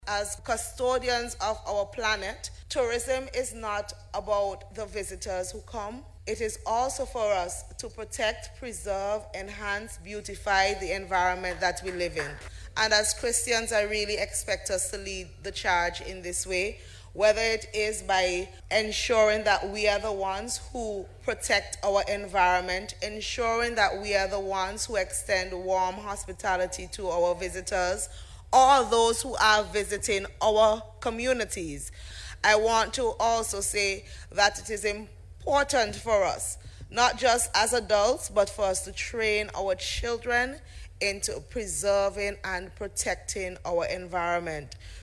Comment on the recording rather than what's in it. Tourism month activities in St. Vincent and the Grenadines kicked off on Sunday with a Church Service at the Streams of Power Church in Sion Hill.